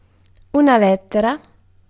Ääntäminen
Vaihtoehtoiset kirjoitusmuodot (vanhentunut) lettre Synonyymit bookstave line Ääntäminen GenAm: IPA : [ˈlɛt.ɚ] US : IPA : [ˈlɛt.ɚ] UK RP : IPA : /ˈlɛtə/ AusE: IPA : /ˈletə/ GenAm: IPA : [ˈlɛɾɚ] Tuntematon aksentti: IPA : /ˈlɛt.ə(ɹ)/